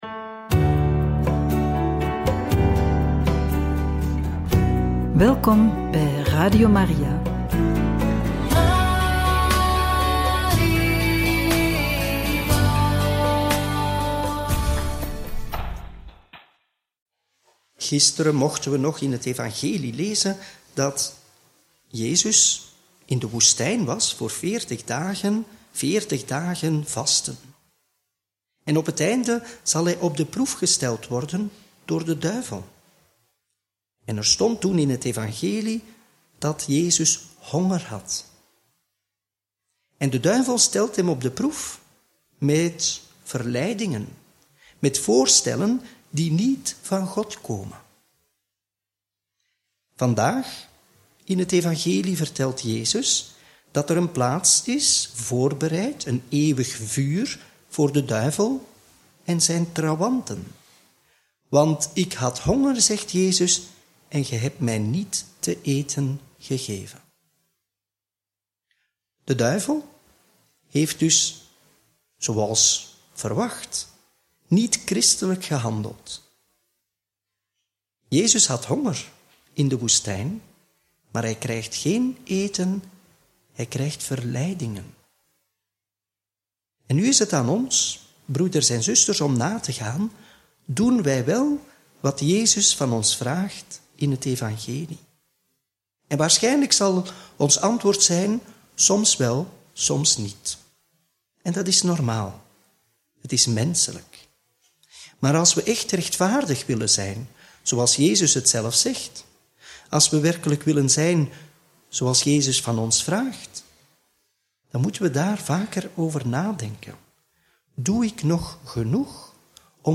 Homilie bij het Evangelie van maandag 10 maart 2025 – Mt 25, 31-46